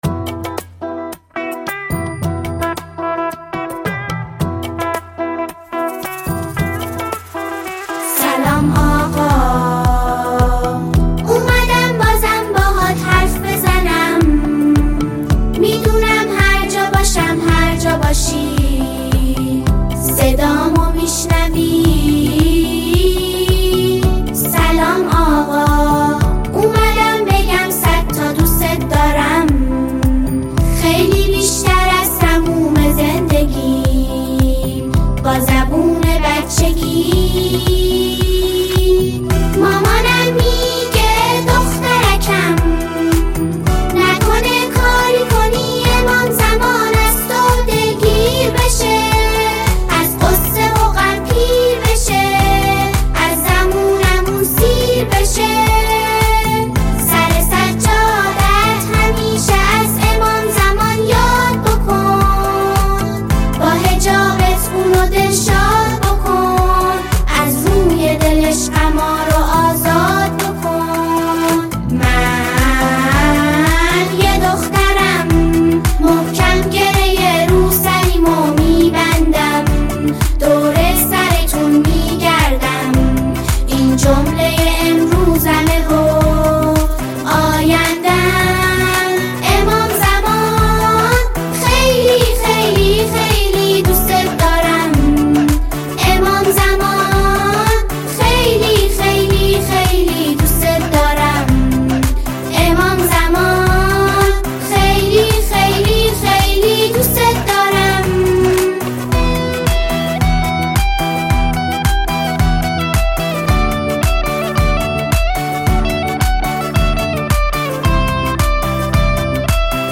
ژانر: سرود ، سرود کودک و نوجوان ، سرود مذهبی ، سرود مناسبتی